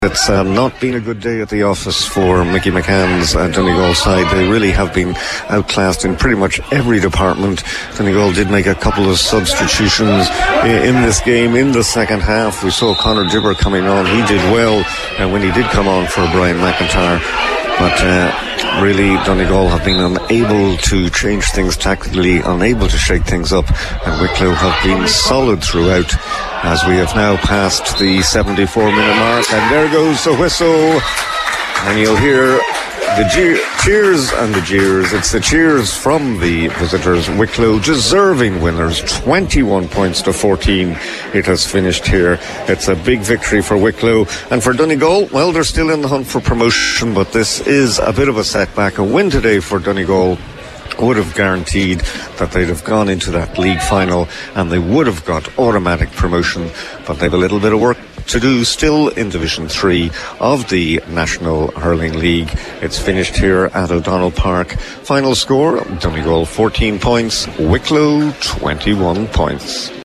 was live at full time in Letterkenny for Highland Radio Sport…